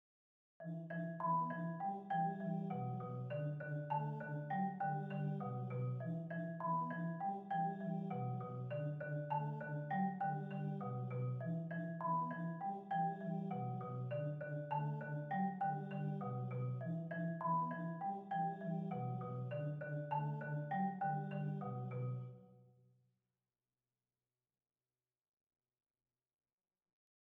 Here is a MIDI realization of the first two vocal parts of "Djunga" or download it here .